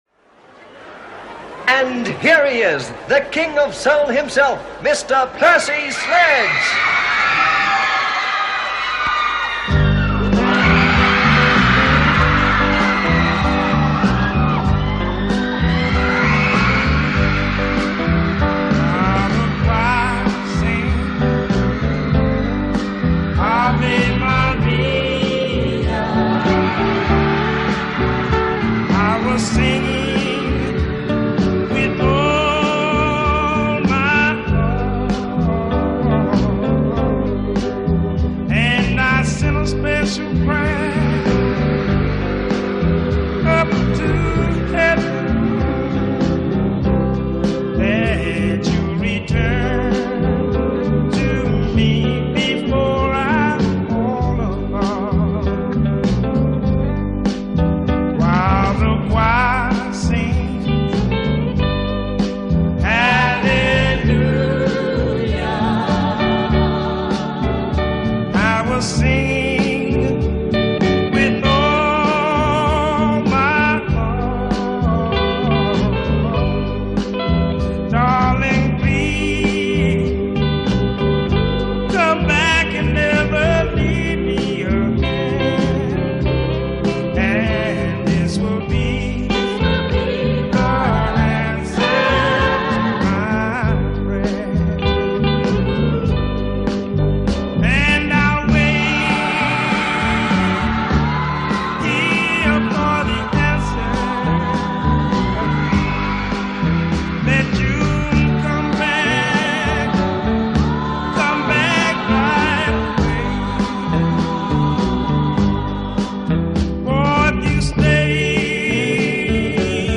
Live In South Africa – May 1970
Soul